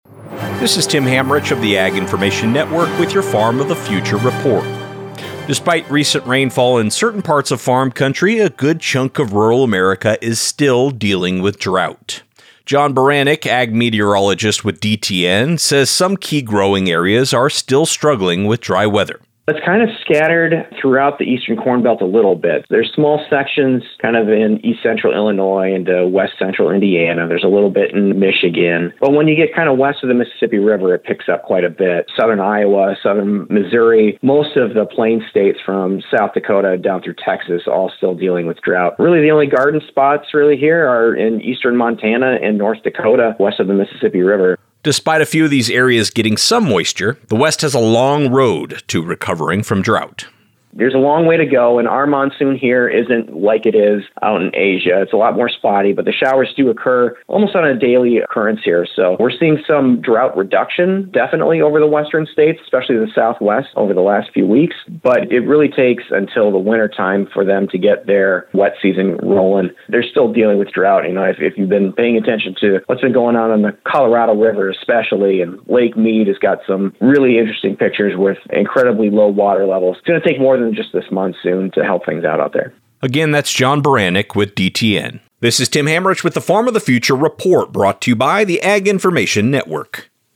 News Reporter
DROUGHT RAINFALL FARM REPORT